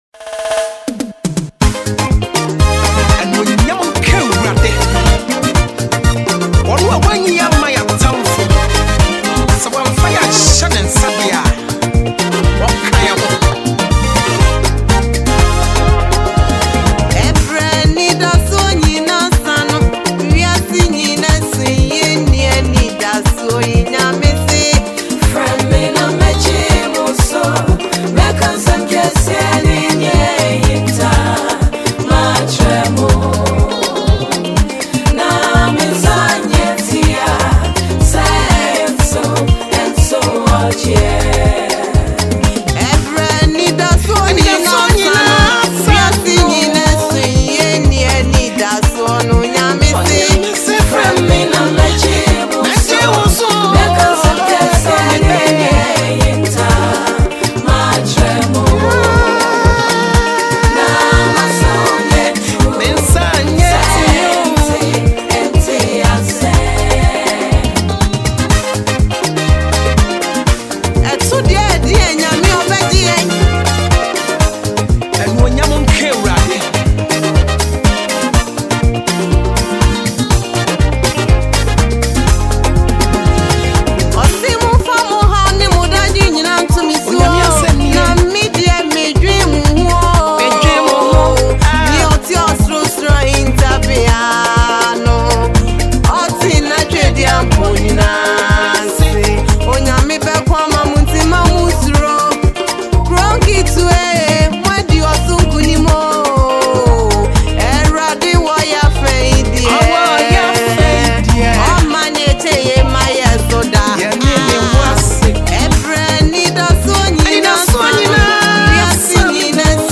GospelMusic